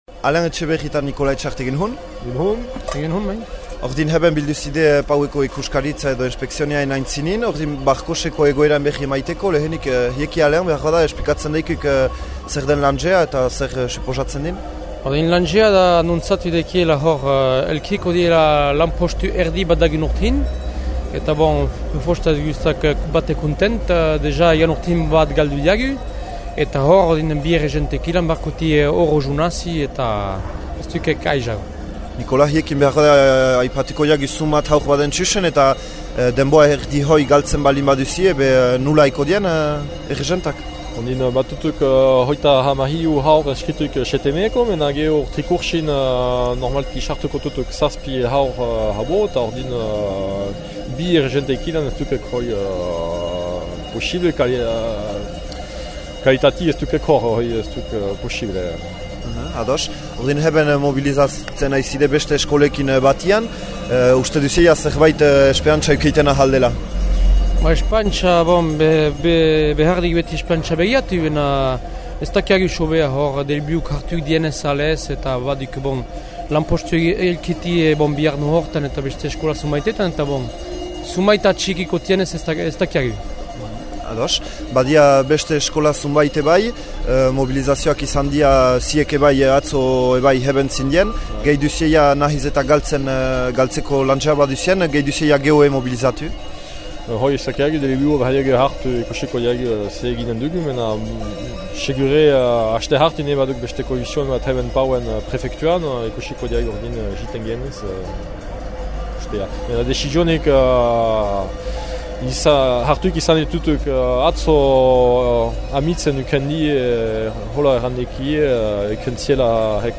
Entzün Barkoxeko eskolako bi aita